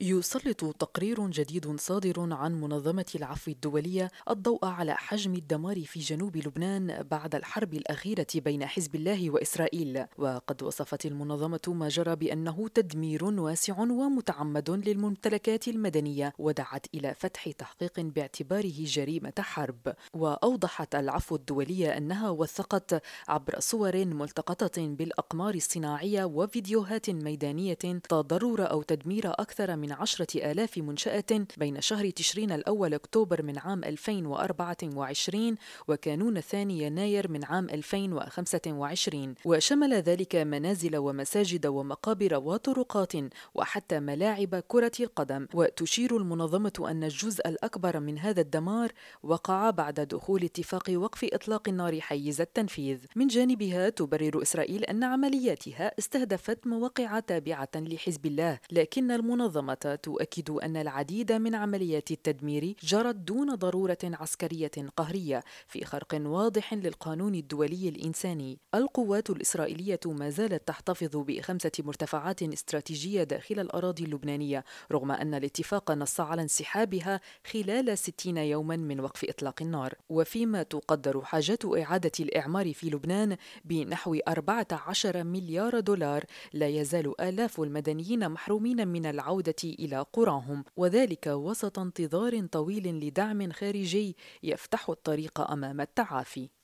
تقرير: العفو الدولية تدعو للتحقيق بتدمير إسرائيل قرى في جنوب لبنان باعتباره “جريمة حرب